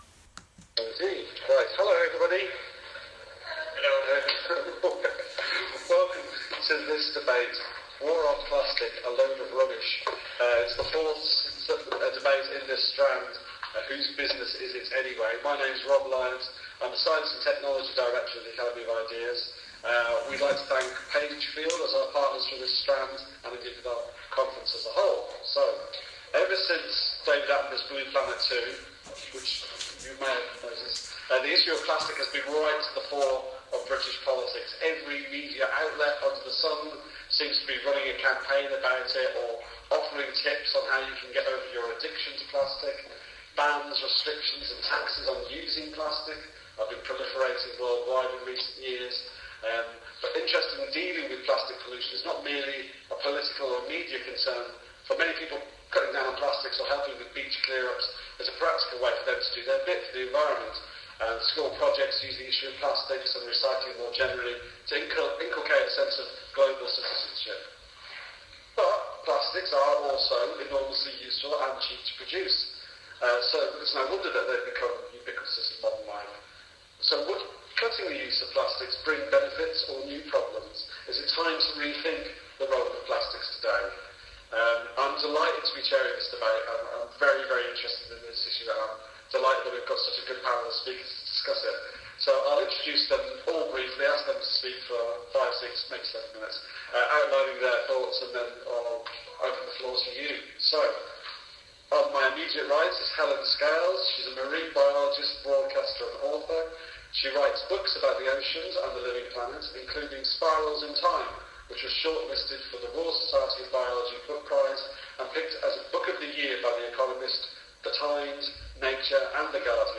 I produced and was one of four speakers who gave seven minute introductions on this topic, before some great audience questions and discussion.
Click on one of the links below for an audio file of the debate.